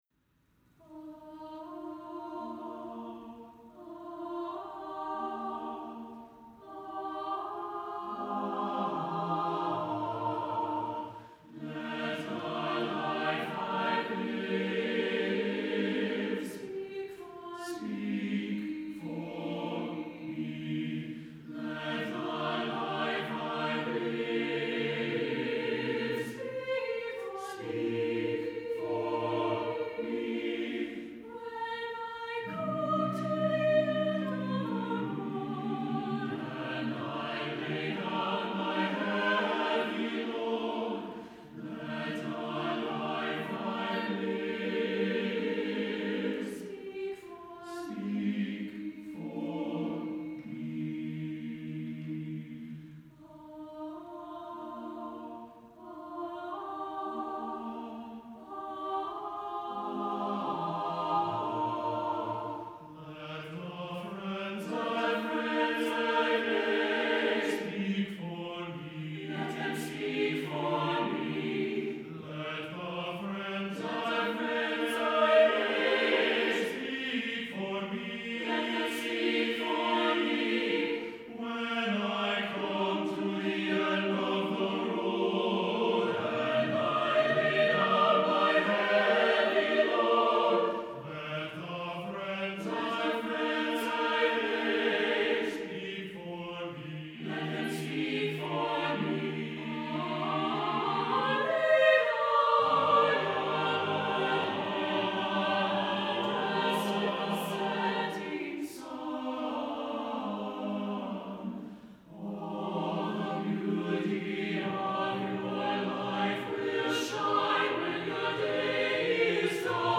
for SATB Chorus (2016)